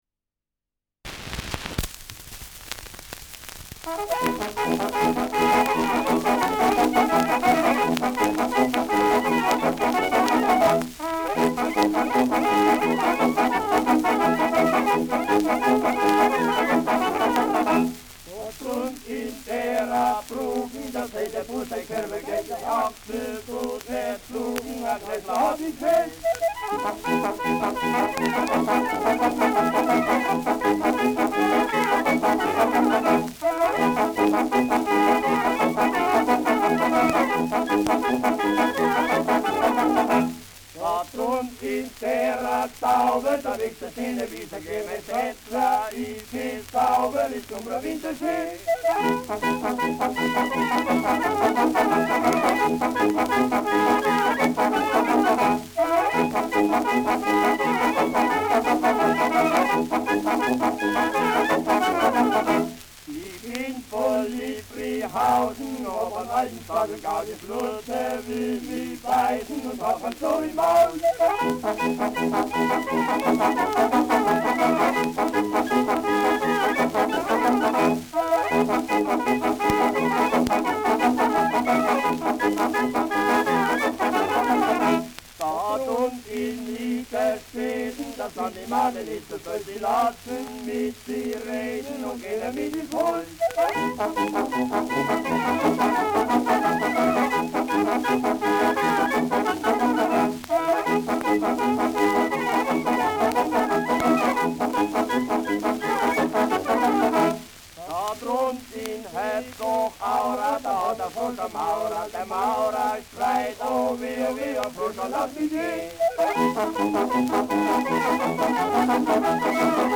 Schellackplatte
präsentes Rauschen
Auf die ohne Musik vorgetragenen Verse spielt die Kapelle die gehörte Melodie nach, wobei sie diese nach ihren Fertigkeiten ausziert und variiert. Für die Aufnahme übernimmt sie hier auch den Part der Sänger (sonst sind das die Kirchweihburschen).